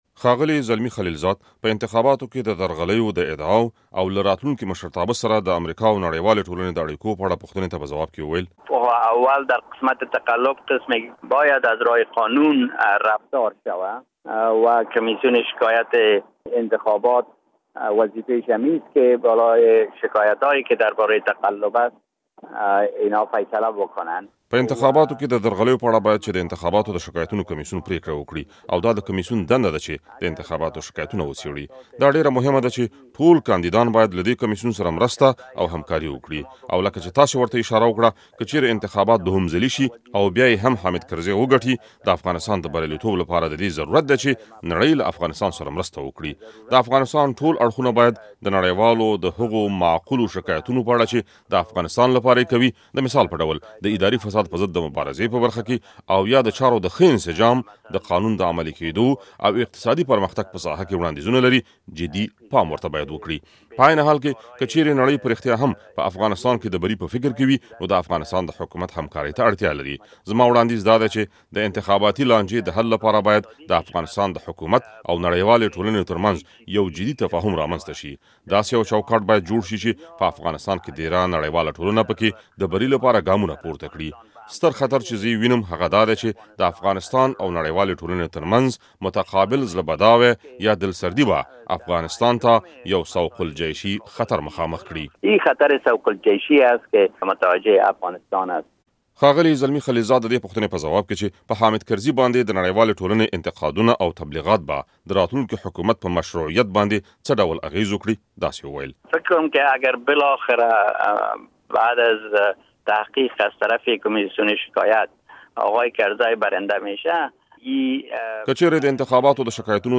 له زلمي خلیلزاد سره مرکه واورﺉ